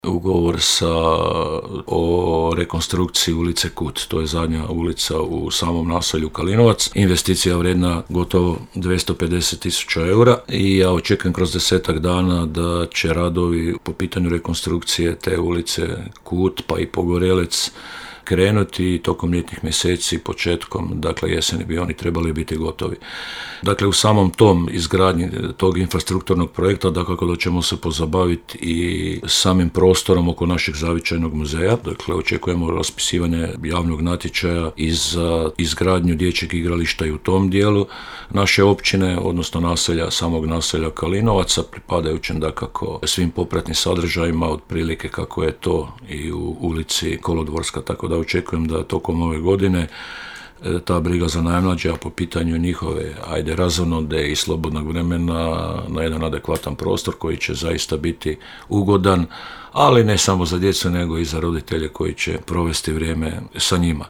– komentirao je za Podravski radio načelnik Sobota.